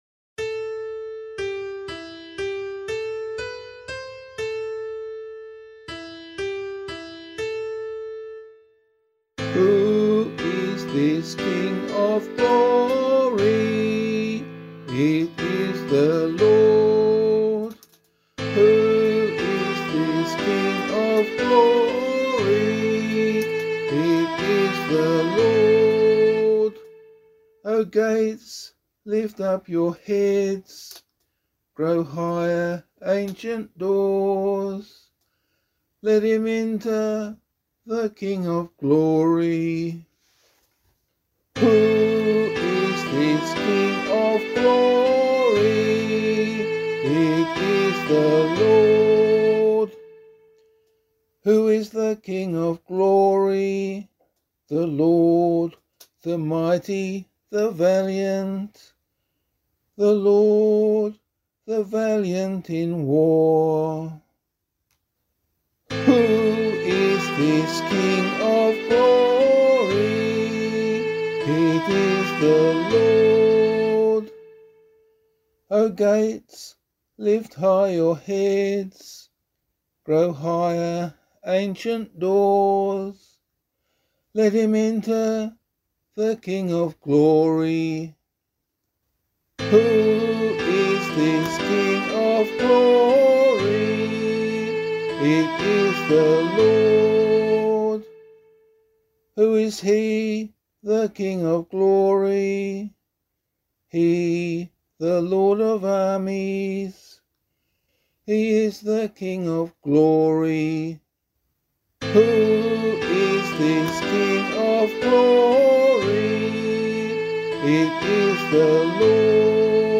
133 Presentation Psalm [LiturgyShare 4 - Oz] - vocal.mp3